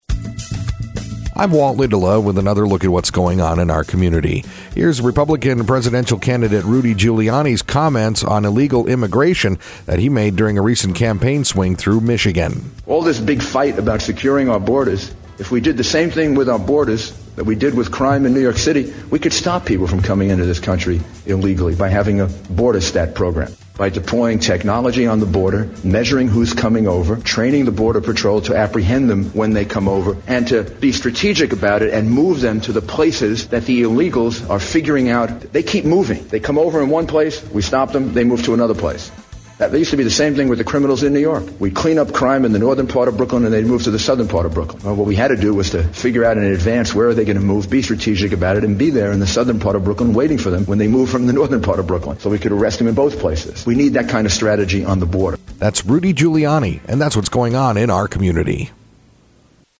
INTERVIEW: Republican Presidential Candidate Rudy Giuliani
A collection of comments by the candidate concerning illegal immigrants and border safety during a campaign swing through Michigan.